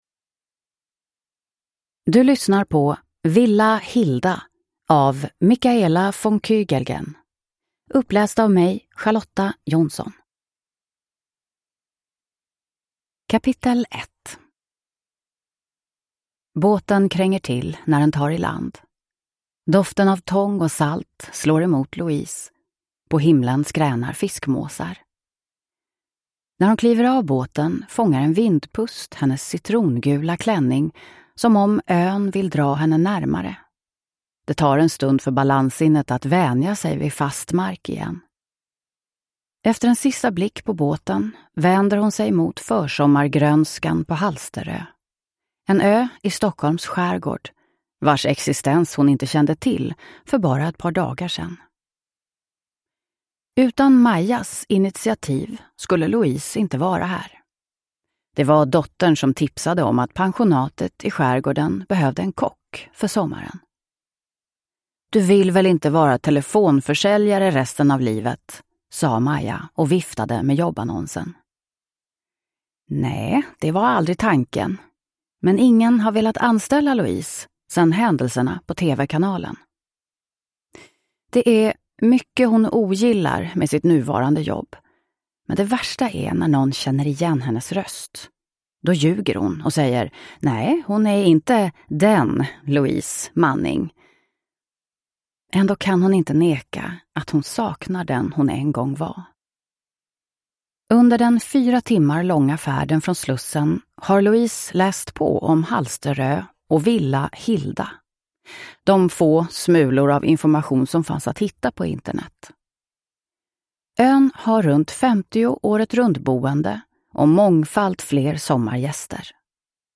Sommar på Villa Hilda (ljudbok) av Michaela von Kügelgen